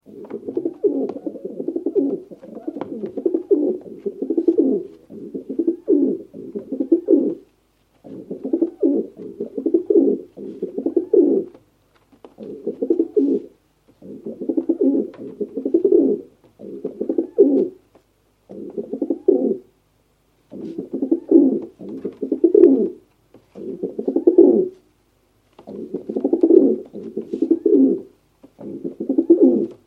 Pigeon - Голубь
Отличного качества, без посторонних шумов.
467_pigeon.mp3